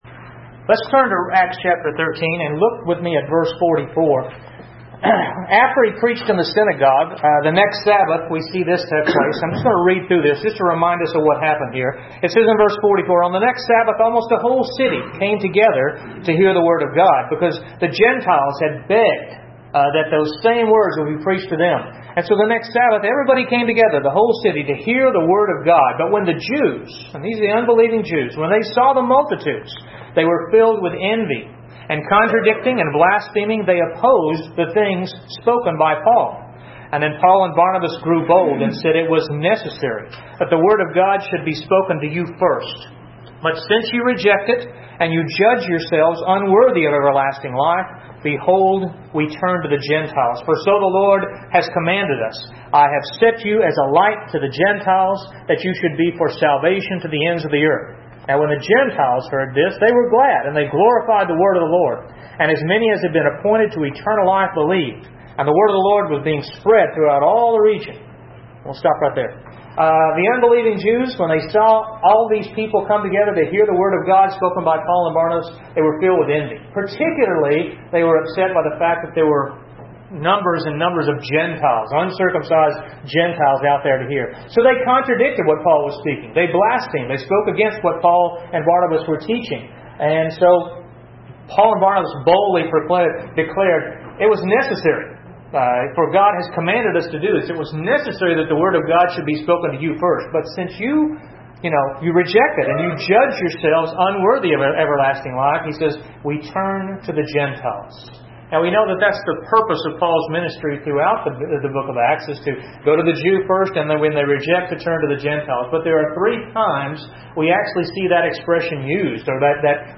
A message from the series "The Book of Acts."